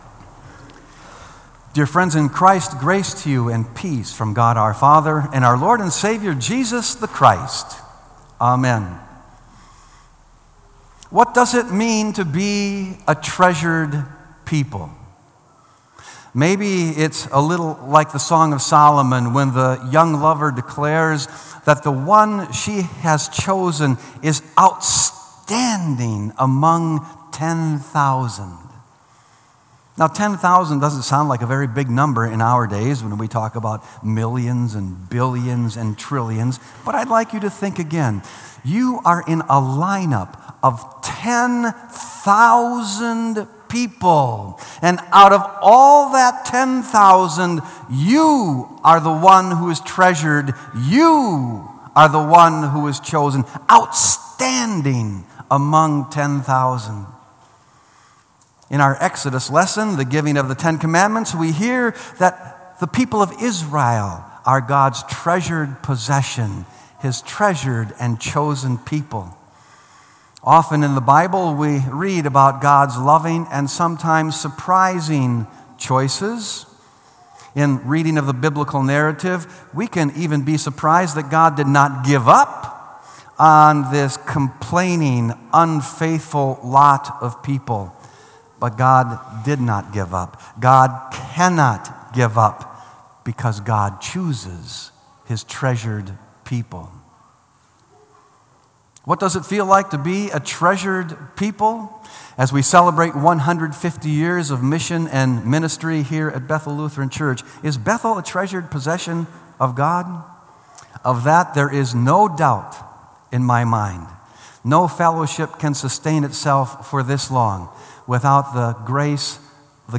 Sermon “A Treasured People”